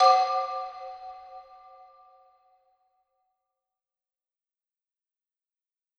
Xylophone Hit 1
Category: Percussion Hits
Xylophone-Hit-1.wav